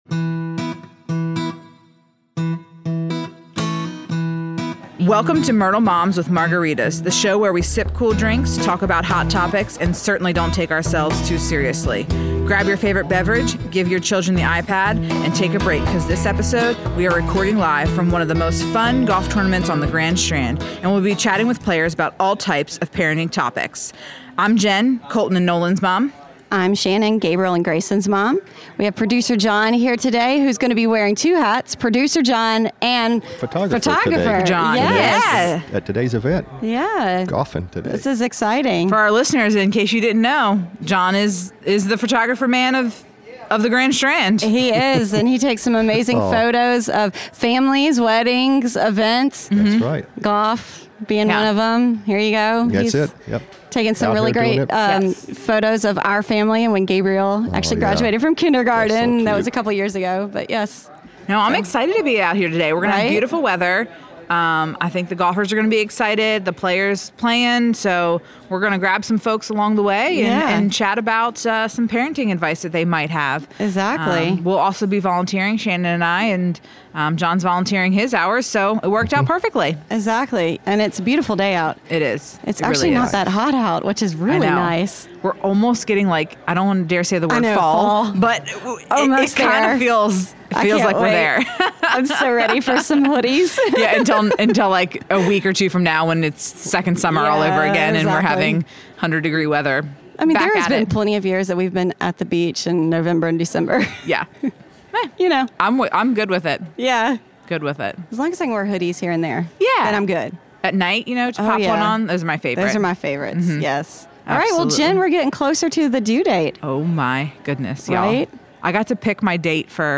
take the show on the road this week to the Myrtle Beach Area Hospitality Association Golf Tournament